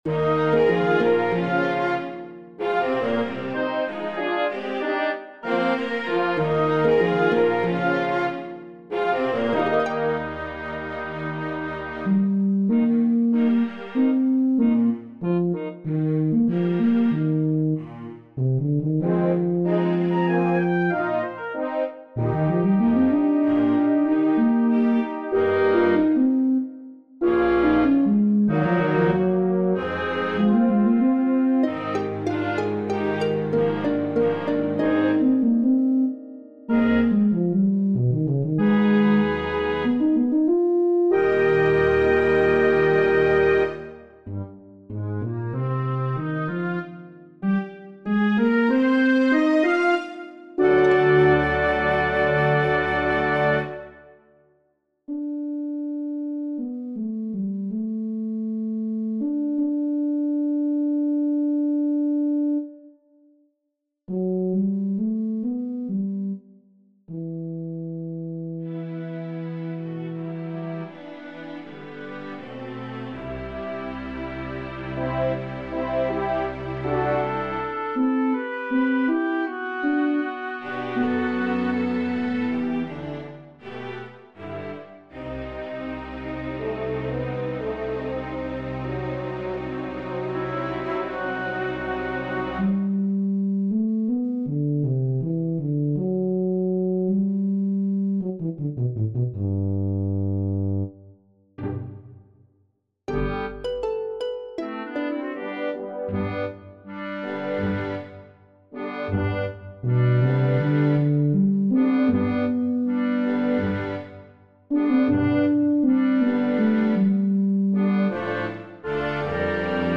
These scores are typically large, orchestral works.